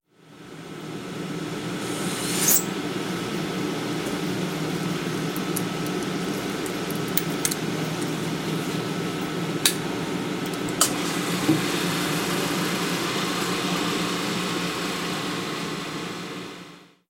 На этой странице собраны звуки микроскопа — от щелчков регулировки до фонового гула при работе.
Звук работы лаборанта в лаборатории с микроскопом